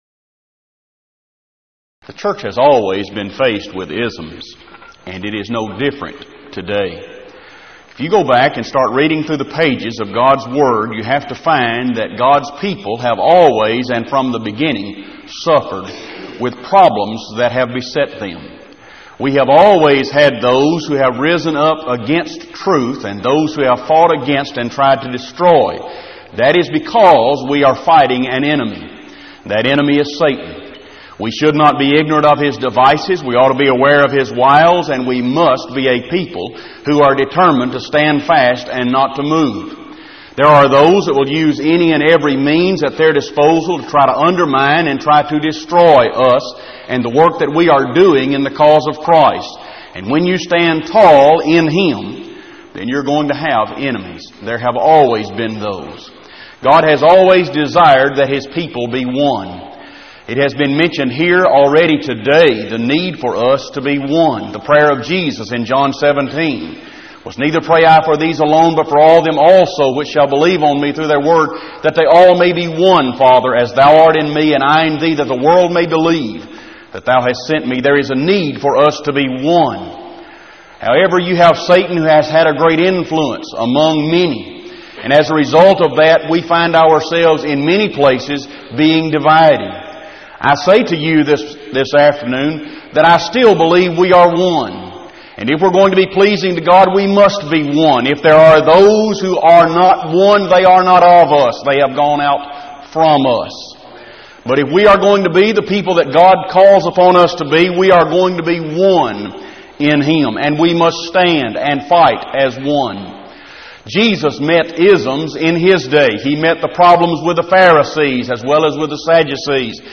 Event: 1997 Power Lectures
lecture